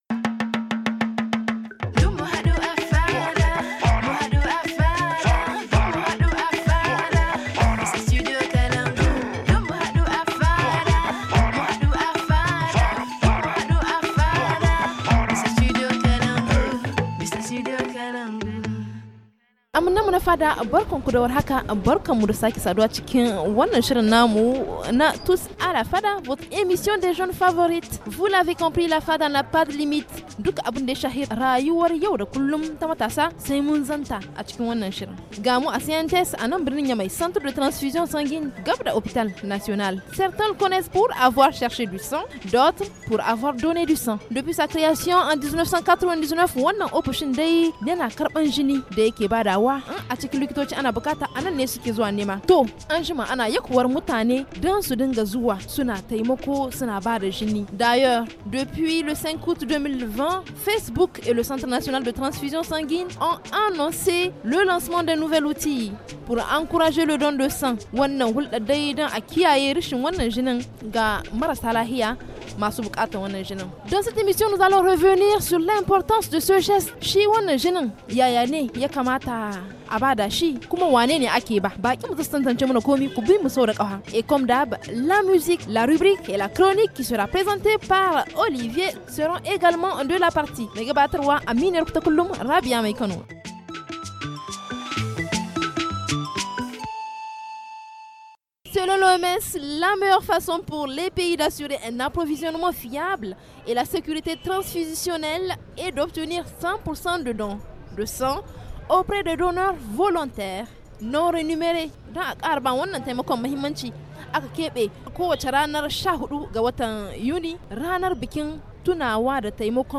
Afin de contribuer pour cette noble cause, la Fada s’est installée dans la cour du CRTS/N de Niamey. Dans ce numéro nous parlerons de l’importance du don de sang. Du prélèvement à la transfusion, comment ça se passe ?